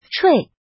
怎么读
chuì
chui4.mp3